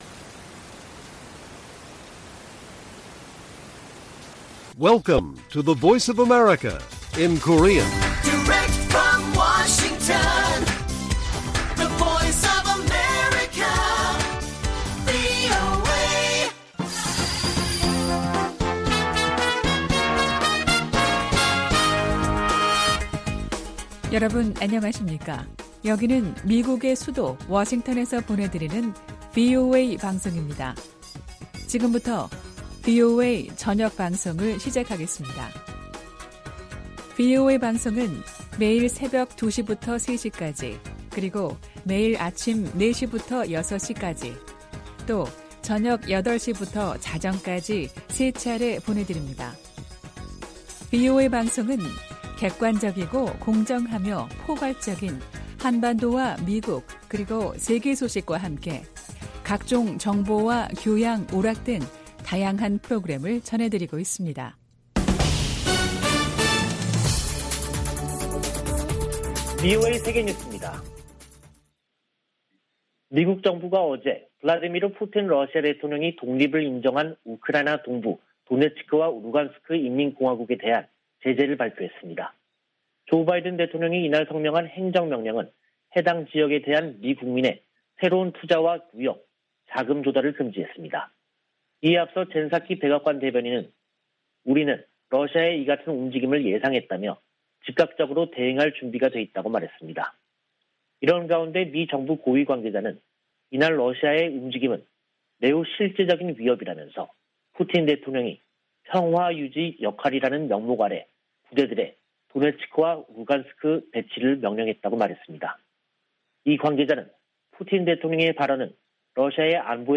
VOA 한국어 간판 뉴스 프로그램 '뉴스 투데이', 2022년 2월 22일 1부 방송입니다. 토니 블링컨 미 국무장관이 왕이 중국 외교부장과 북한 문제와 우크라이나 사태 등에 관해 전화협의했습니다. 조 바이든 미국 행정부가 러시아 군의 우크라이나 침공에 대응하는 조치를 구체화하는 가운데 한국 등 아시아 동맹국의 인도주의 지원 등이 거론되고 있습니다. 김정은 북한 국무위원장이 시진핑 중국 국가주석에게 친서를 보내 대미 공동전선 협력을 강조했습니다.